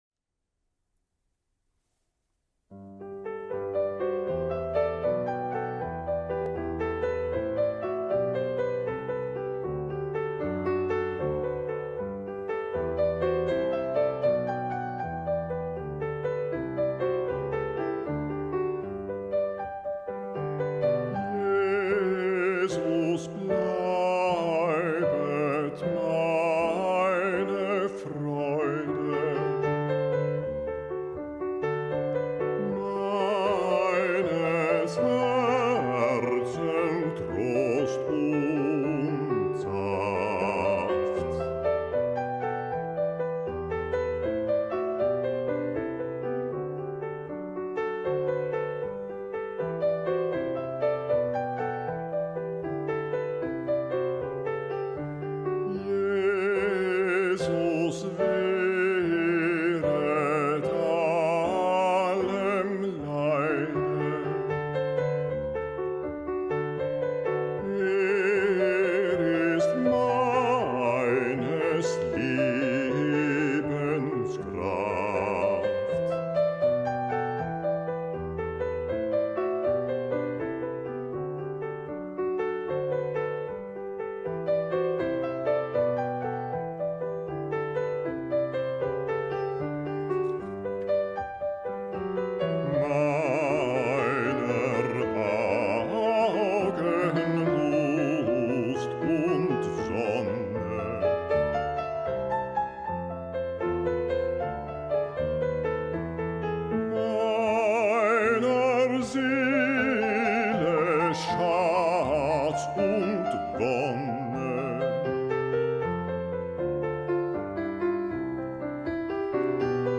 basse
cantate-147-jesus-bleibet-basse.mp3